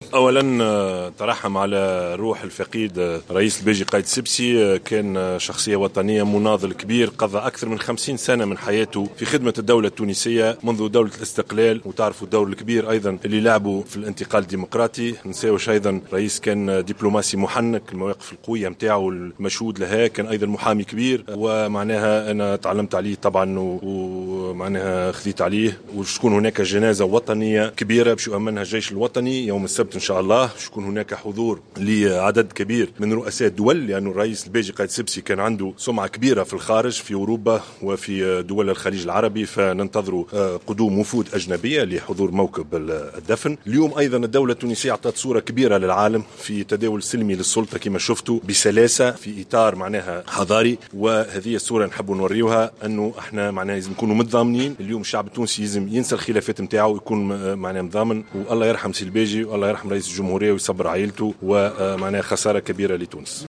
أعلن رئيس الحكومة يوسف الشاهد، أنه سيتم تنظيم جنازة وطنية كبرى، يؤمنها الجيش الوطني بعد غد السبت، لتوديع الرئيس الراحل الباجي قايد السبسي، وذلك في تصريح صحفي عقب لقاء جمعه عشية اليوم الخميس، برئيس الدولة المؤقت محمد الناصر ووزراء الدفاع الوطني والداخلية والعدل والخارجية، بمجلس نواب الشعب بباردو.